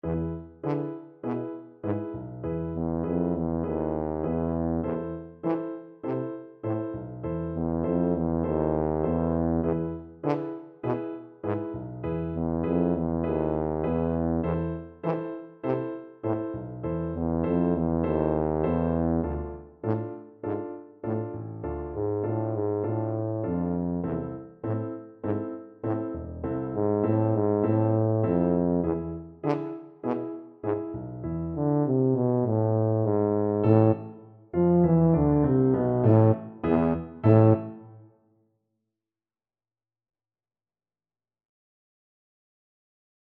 A minor (Sounding Pitch) (View more A minor Music for Tuba )
Tempo di Tango
4/4 (View more 4/4 Music)